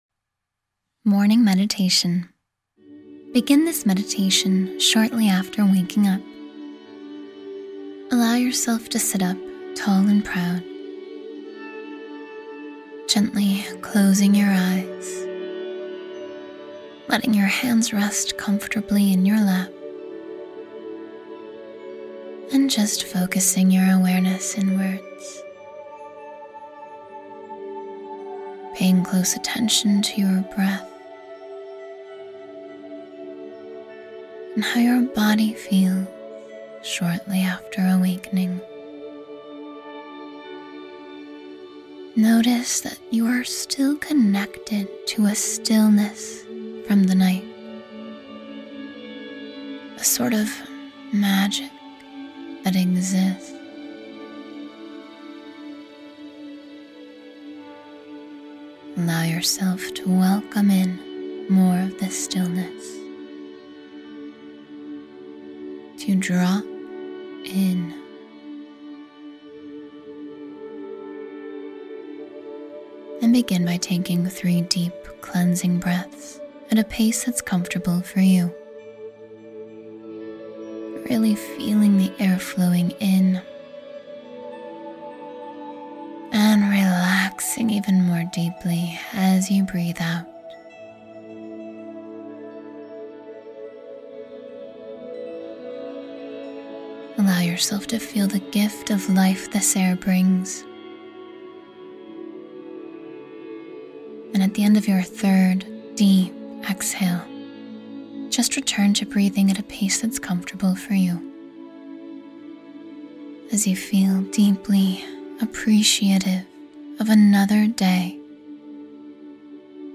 Morning Meditation: Today Is Your Day for Success